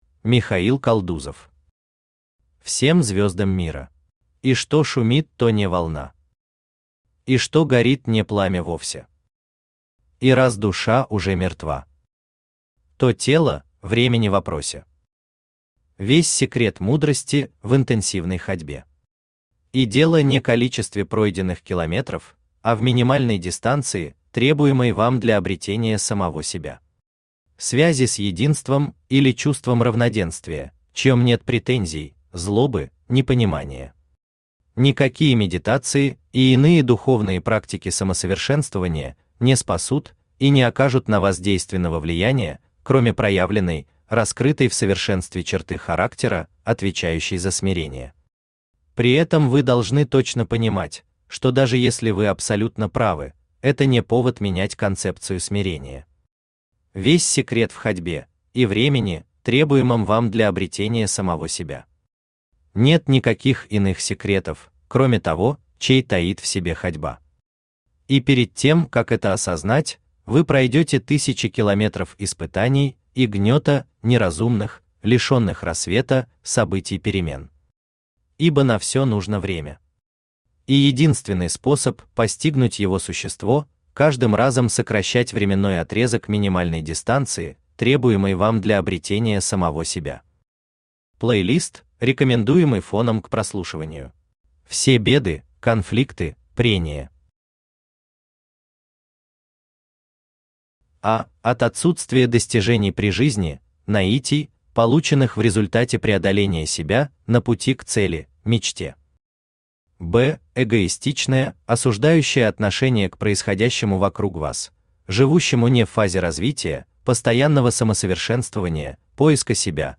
Аудиокнига Всем звёздам мира | Библиотека аудиокниг
Aудиокнига Всем звёздам мира Автор Михаил Константинович Калдузов Читает аудиокнигу Авточтец ЛитРес.